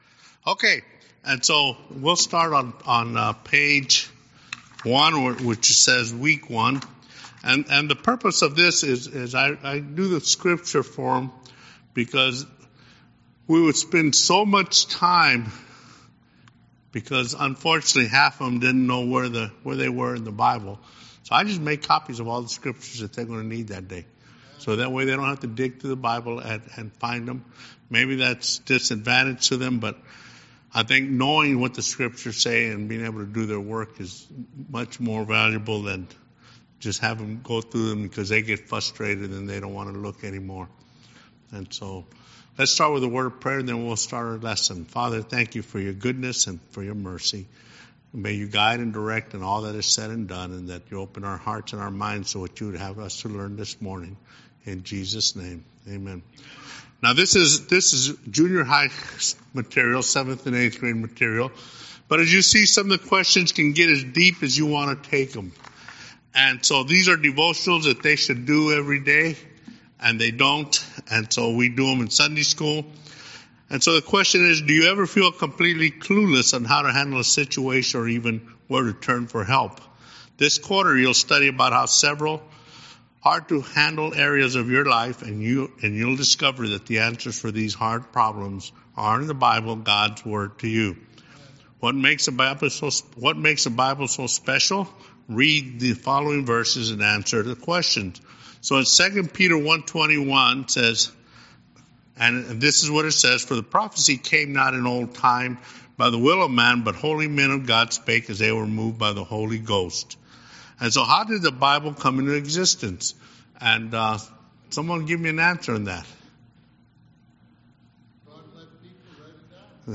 Sunday School Recordings
Series: Guest Speaker